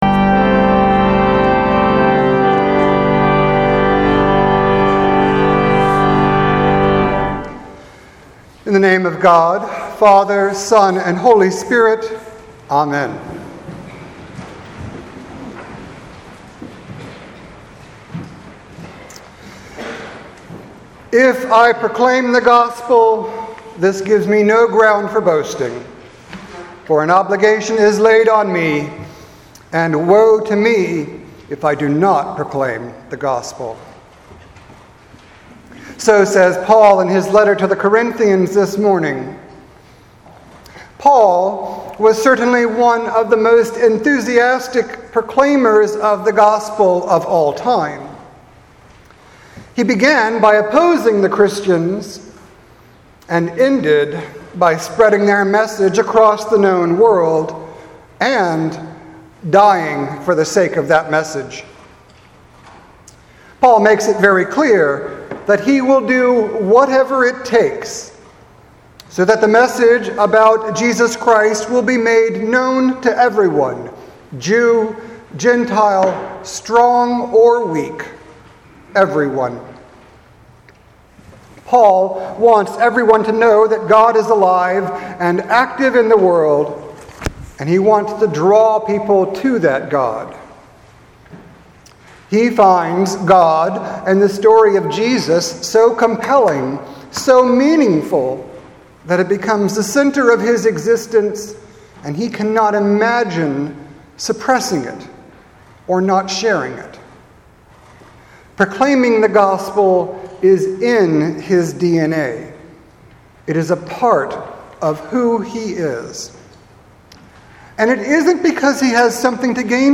sermon-2-4-18.mp3